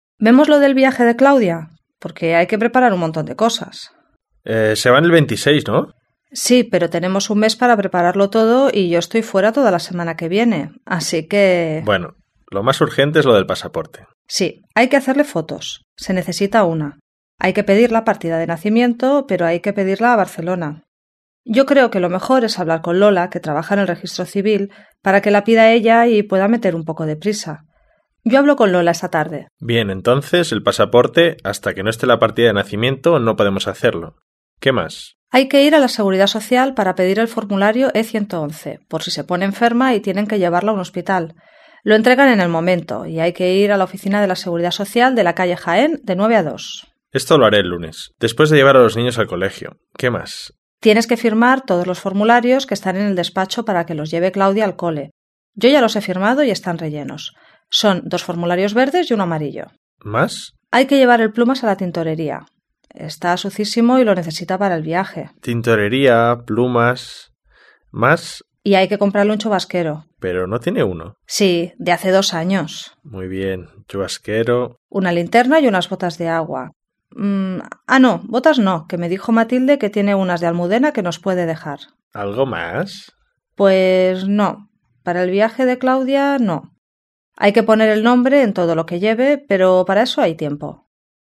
3. Rosa y Emilio hablan sobre lo que necesita su hija Claudia para un viaje que la niña va a hacer con el colegio a Francia. Lea la lista y escuche la conversación para saber qué tiene que hacer Emilio.